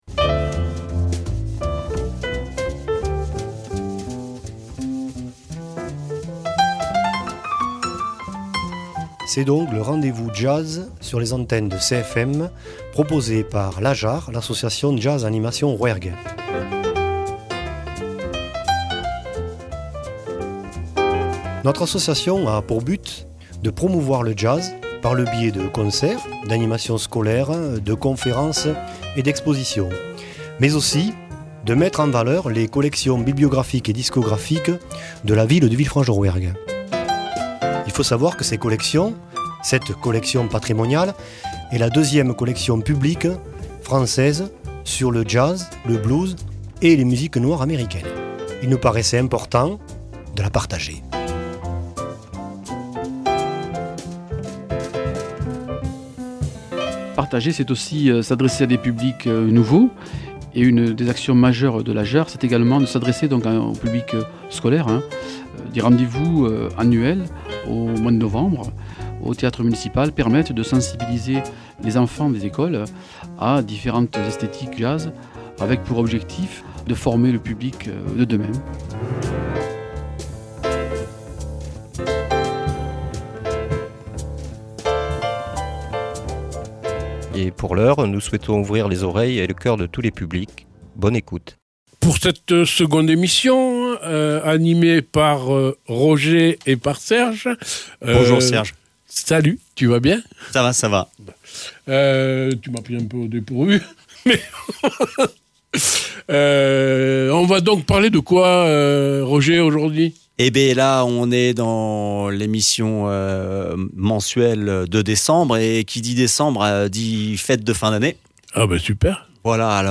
Nous allons donc proposer et écouter un panel de chants traditionnels autour de noël interprétés par des grands noms du jazz.